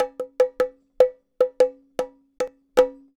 Bongo Fill 03.wav